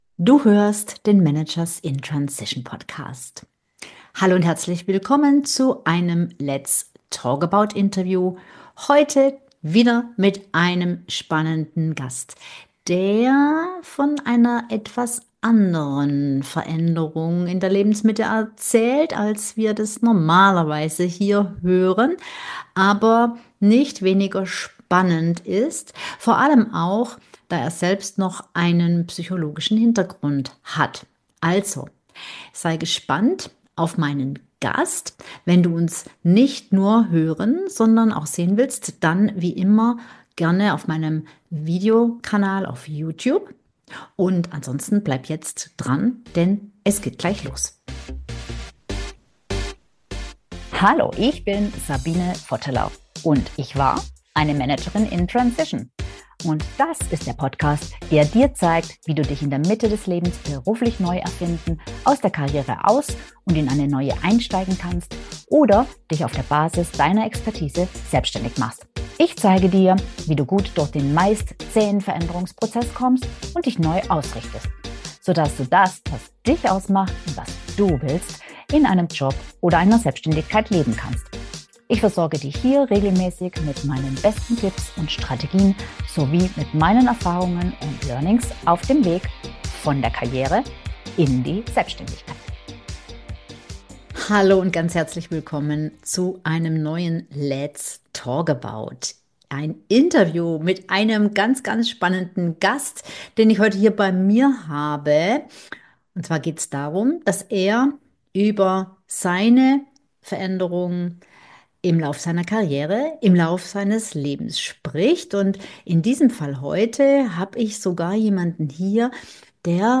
Ein inspirierendes Gespräch für alle, die „in Transition“ sind.